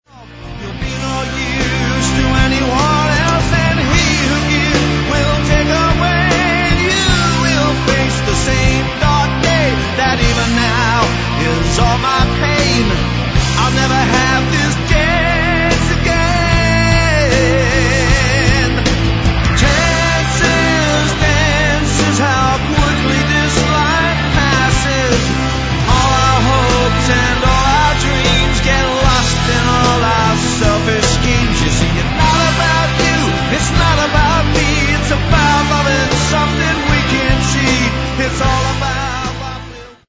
lead vocals, guitar, keyboards, dobro
electric and acoustic guitars
bass guitar
drums
percussion
7-string guitar
acoustic guitar, backing vocals
backing vocals